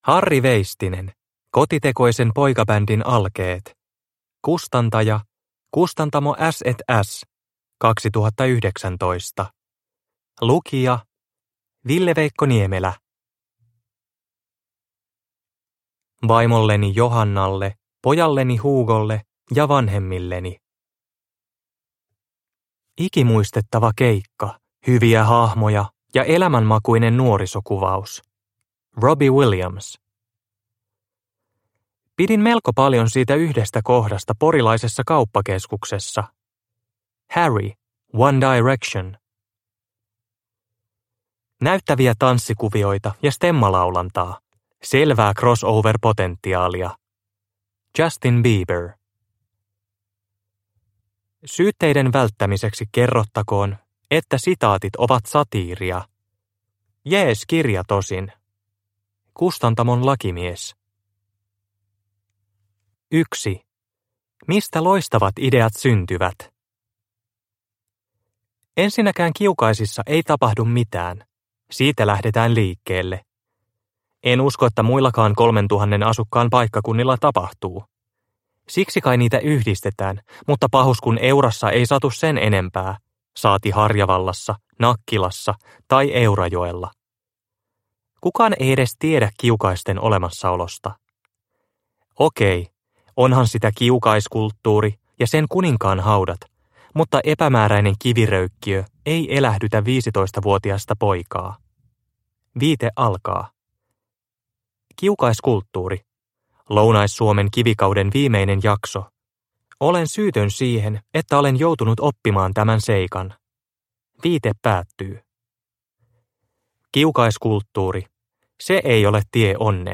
Kotitekoisen poikabändin alkeet – Ljudbok – Laddas ner